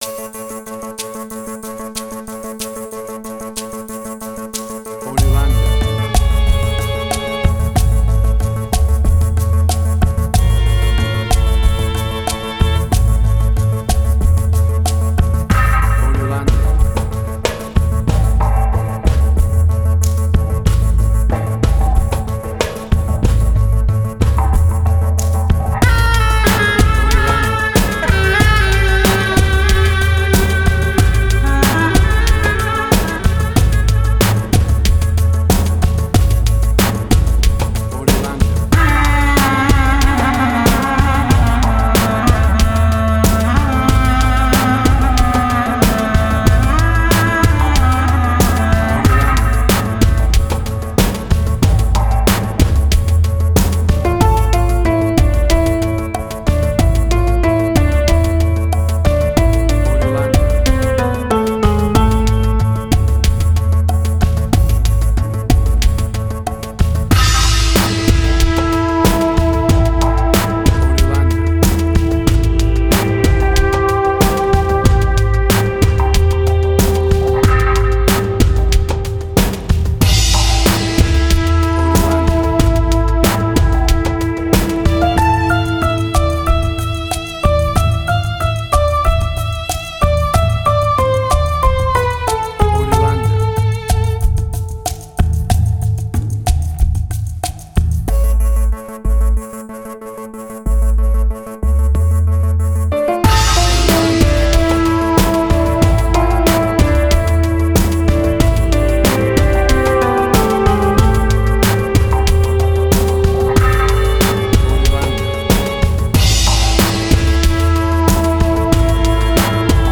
Suspense, Drama, Quirky, Emotional.
Tempo (BPM): 93